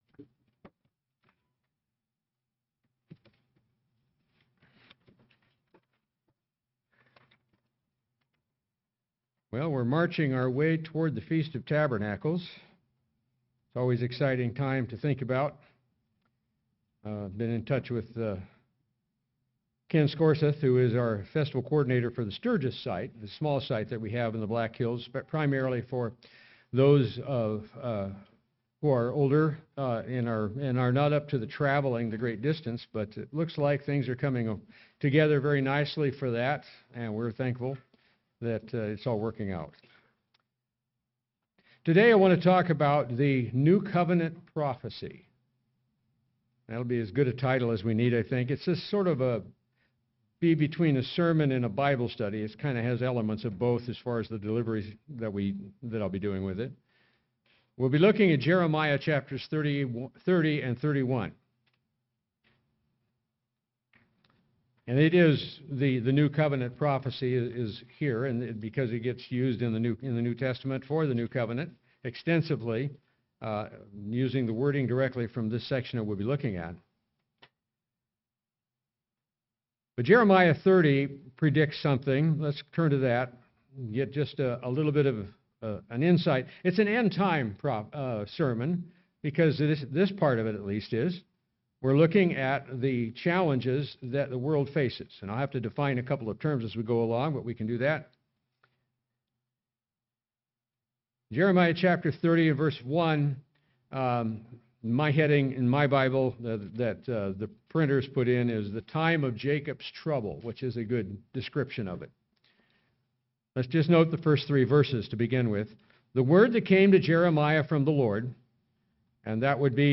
Given in Sioux Falls, SD Watertown, SD